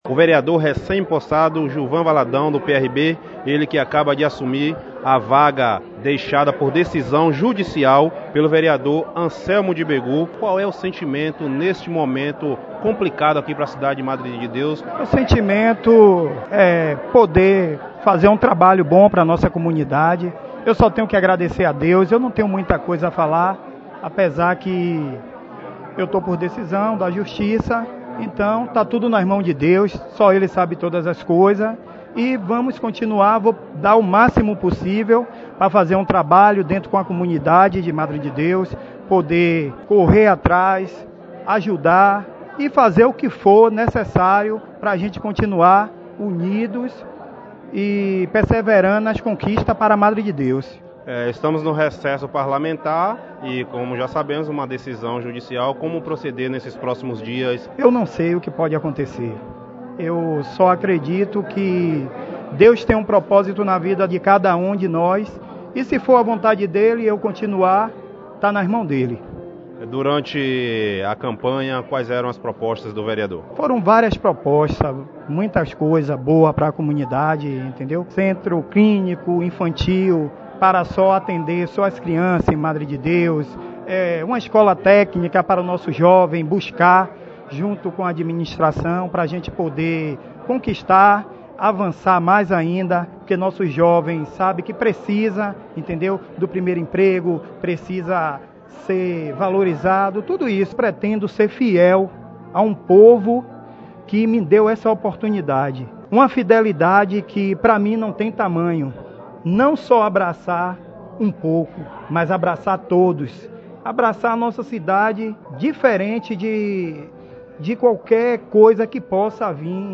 Hoje depois de empossado como parlamentar, o vereador falou sobre o momento e as espectativas no cargo.
JILVAN-VALADAO-MADRE-18.7.18.mp3